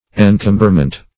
Search Result for " encomberment" : The Collaborative International Dictionary of English v.0.48: Encomberment \En*com"ber*ment\, n. [See Encumberment .]